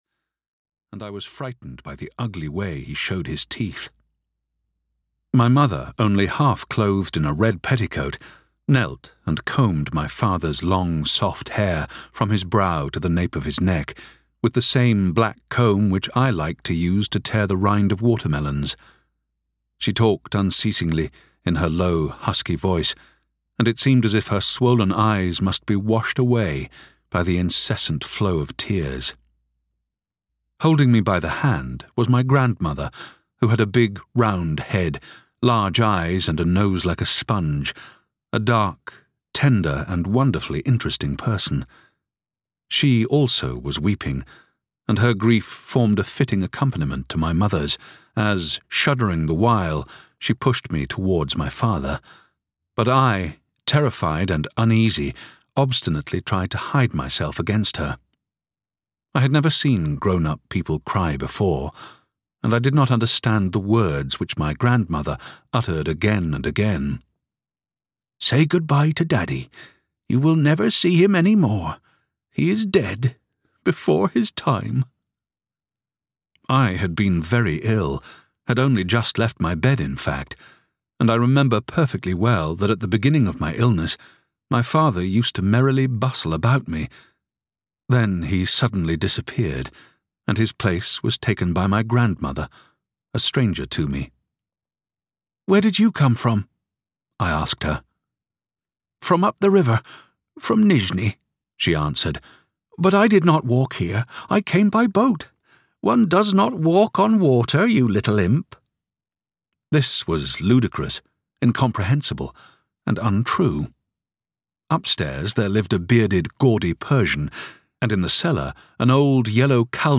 Audio knihaMy Childhood (EN)
Ukázka z knihy